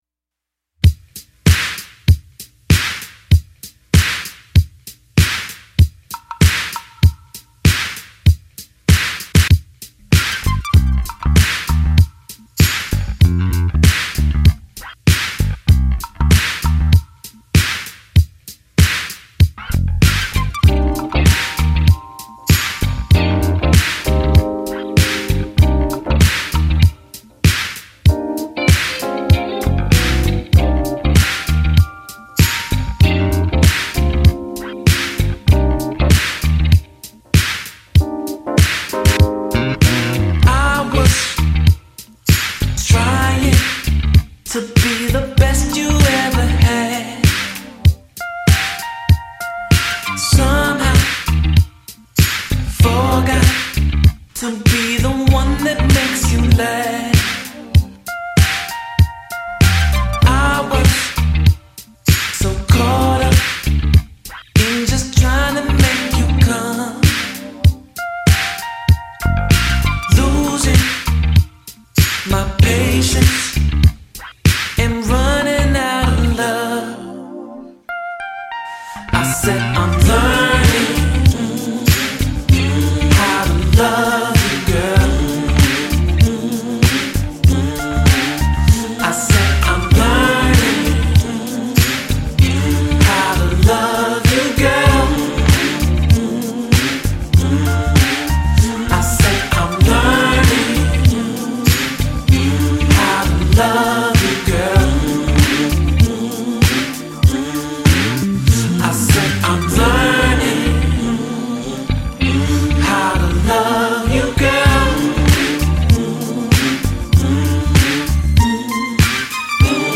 グルーヴィーなベースやシンセが印象的な、PPUやミネアポリス近辺を彷彿とさせる粘度の高いブギー・ファンクを披露！